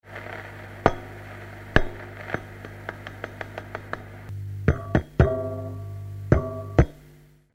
Roehre_Mikrofonie.mp3